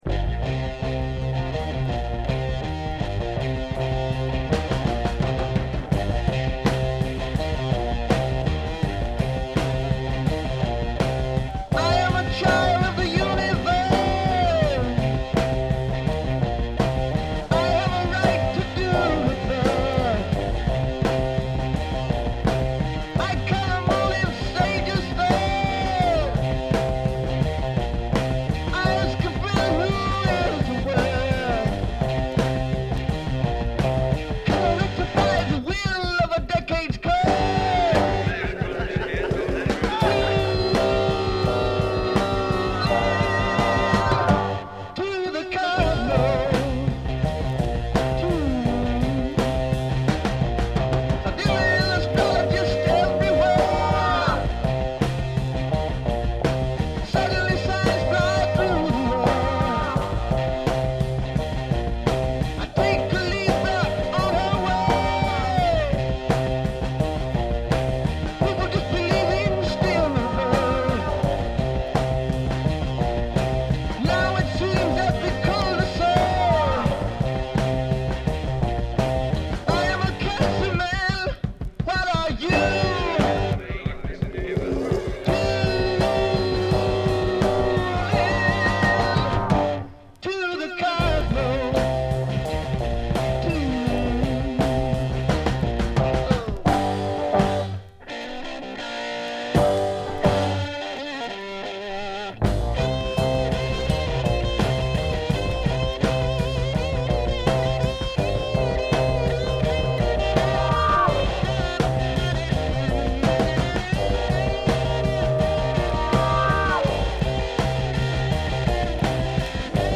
Just great guitar throughout and snappy drums too.